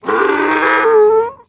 мул рычит